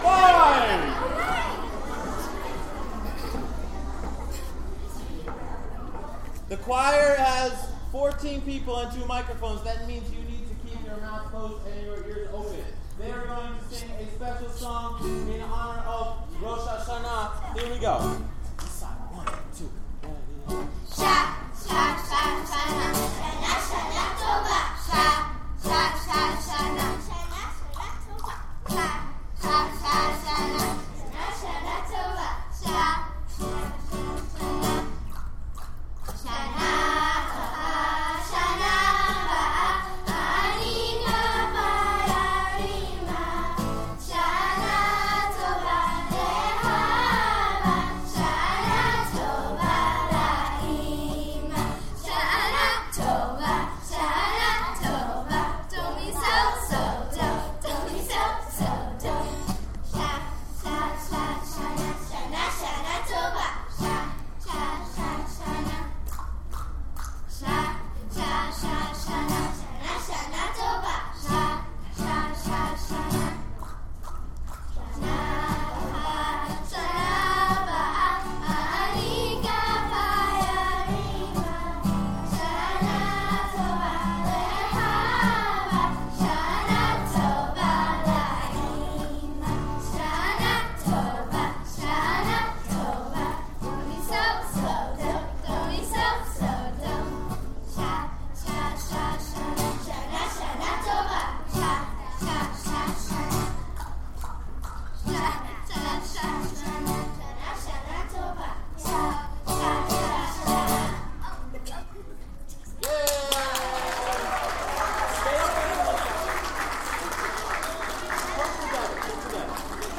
2014-09-12 Choir: "Shana Tova"